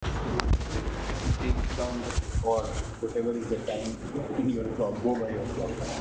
Here we host our database "IUEC (IIITD Urban Environment Context) database" which contains distress (scream and cry sounds) and sounds of 6 environmental contexts collected from mobile phones, movies and Internet.
Indoor sounds     kitchen.wav
room_talk.wav